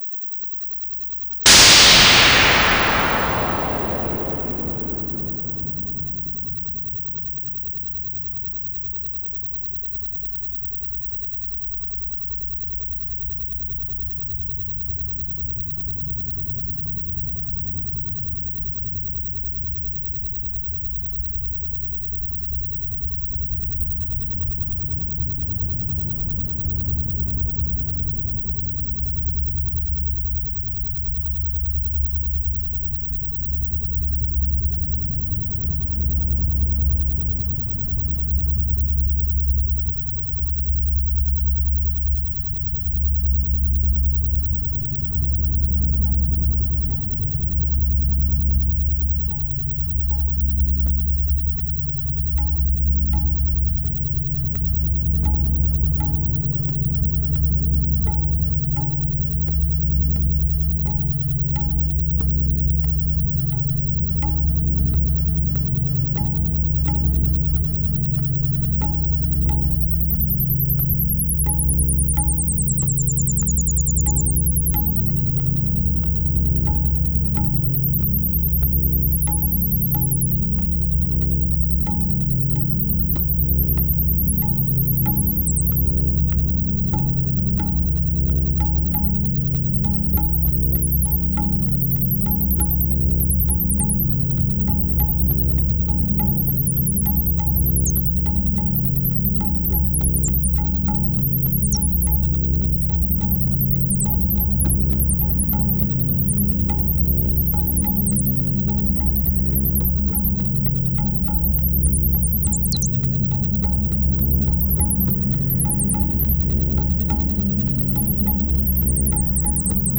Ne pouvant y assister j’avais proposé à la collègue organisatrice d’y participer virtuellement avec une petite pièce de musique électronique « — Tu composes ?
Pour la première fois j’ai ajouté aux sons électroniques (Korg Odyssey) une piste rythmique au tabla, un simple tintal à 16 temps.